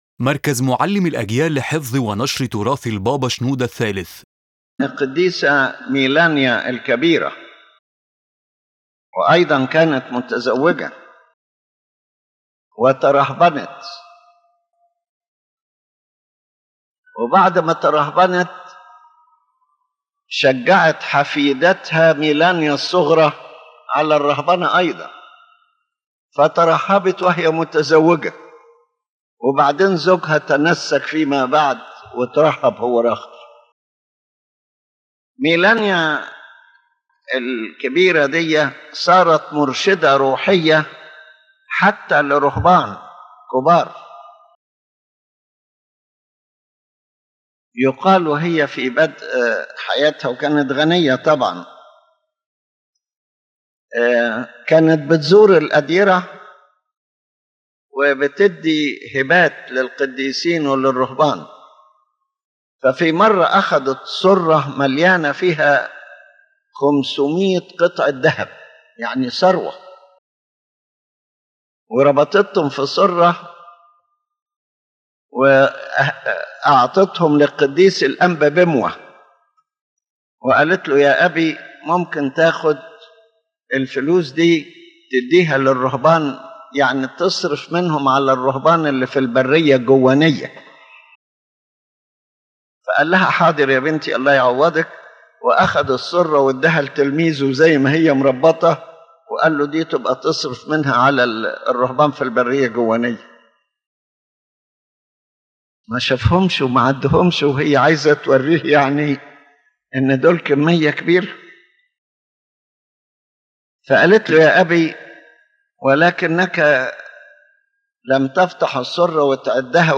His Holiness Pope Shenouda III spoke about Saint Melania the Elder, who was born into a wealthy family, married, and later became a nun.